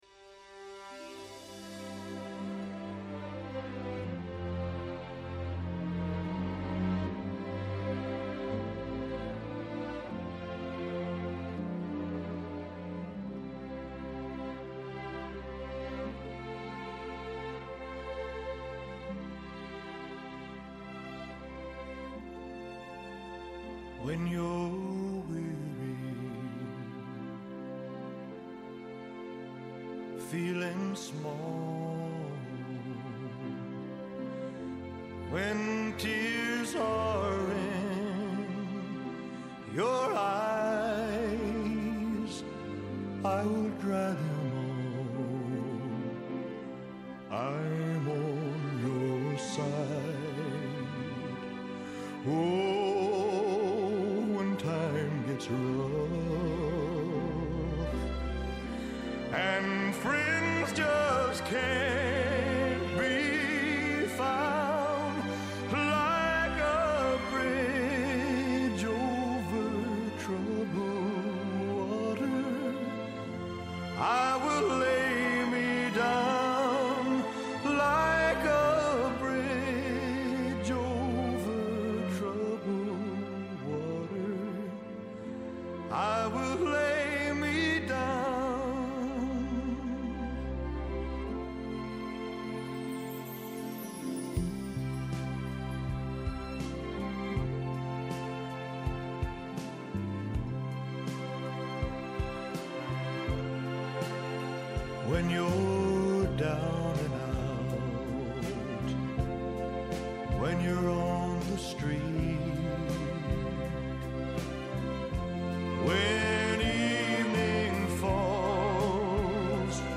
Σήμερα καλεσμένοι τηλεφωνικά